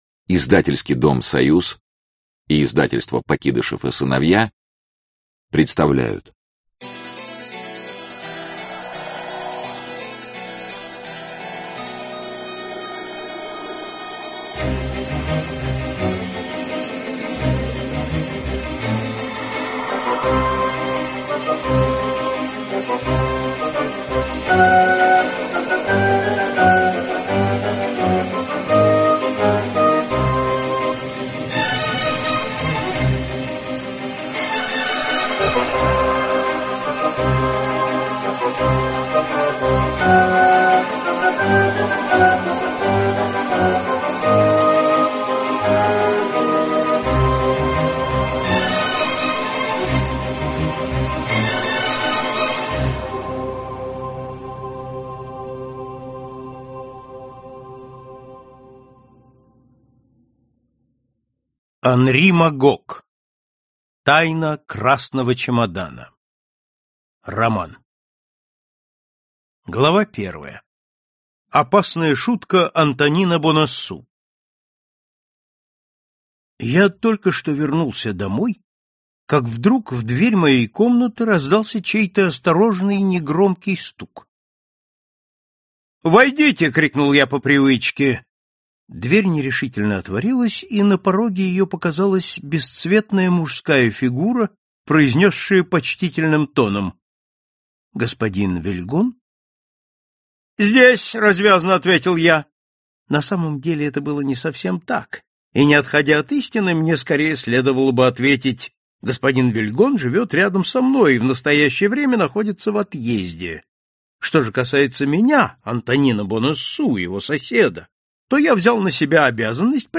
Аудиокнига Тайна красного чемодана | Библиотека аудиокниг